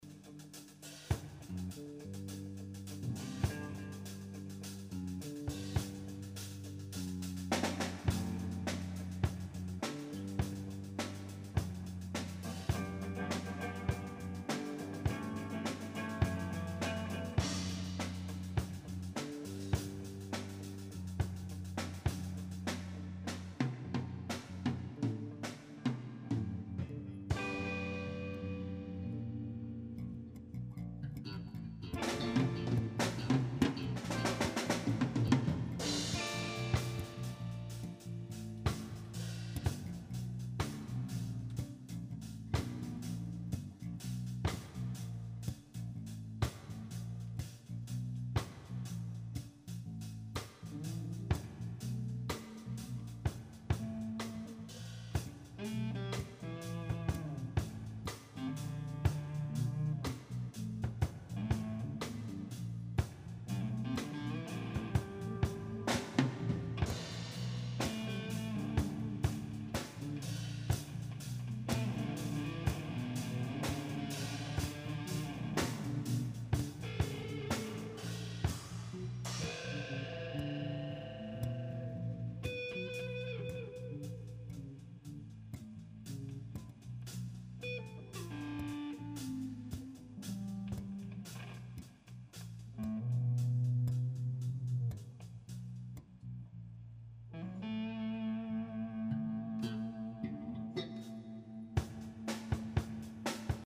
Prises de sons live ce qui implique parfois des petits défauts.
@ Répétition du 14 décembre 2008 @
Lien pour télécharger la partie 02 (Impro)